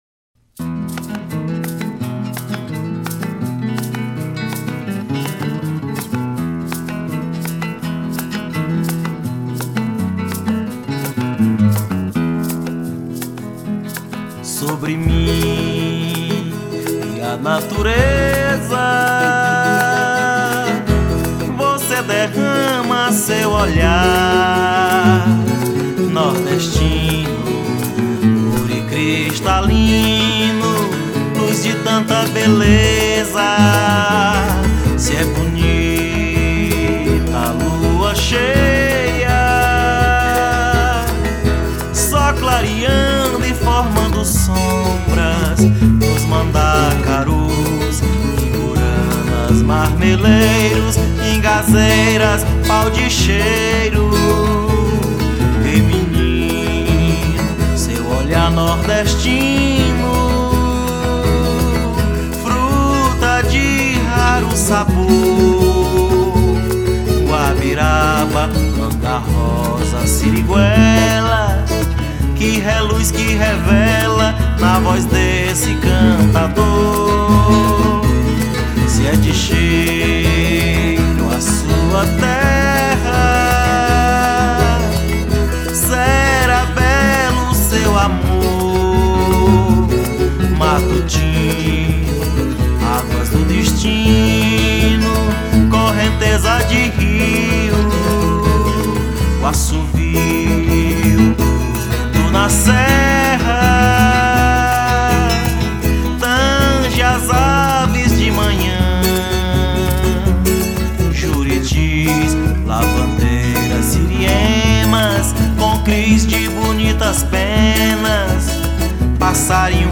1596   03:44:00   Faixa:     Canção Nordestina